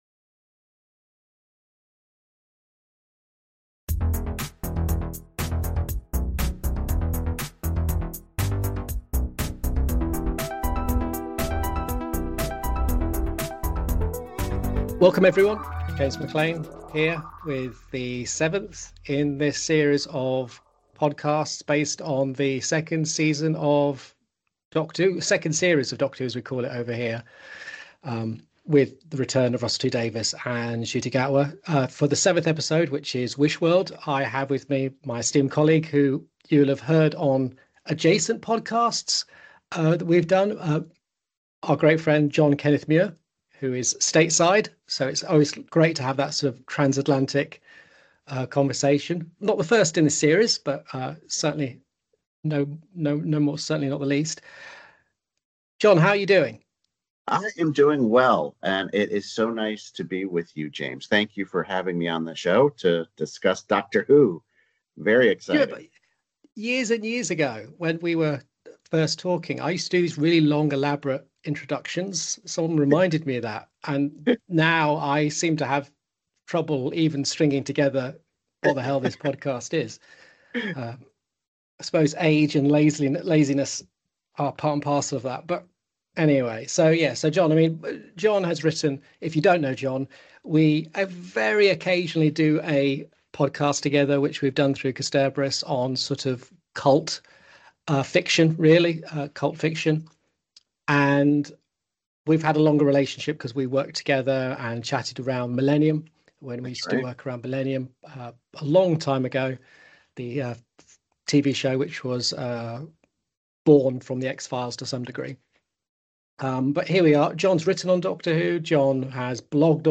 This audio version is a truncated edit of that conversation, but we’ll be bringing the cut portions to you in future.